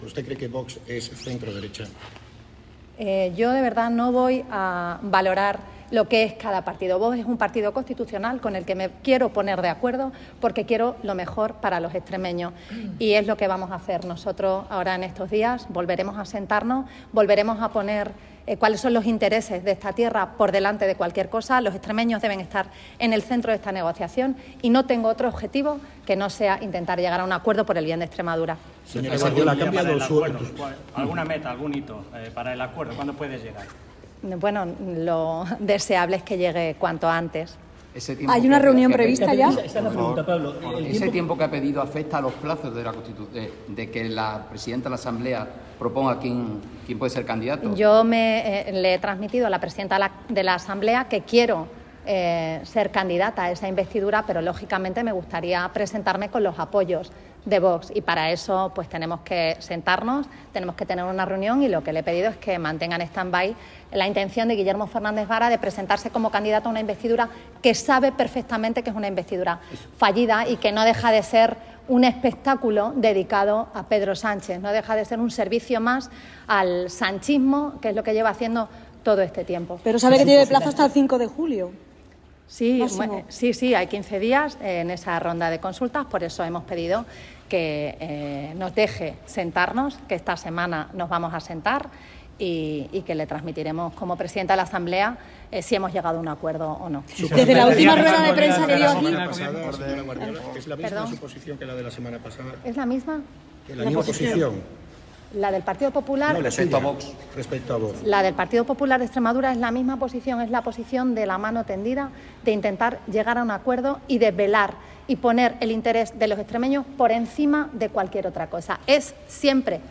Las declaraciones de María Guardiola, a continuación: